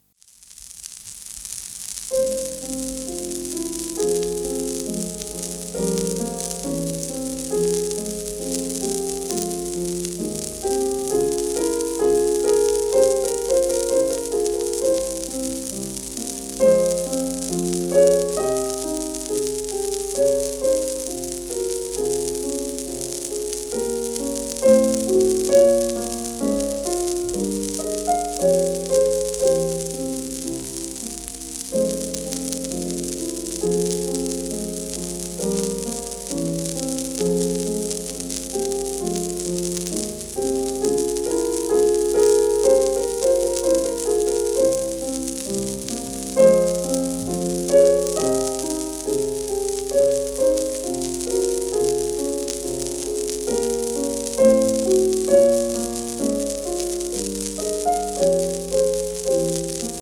ディヌ・リパッティ(P:1917-50)
シェルマン アートワークスのSPレコード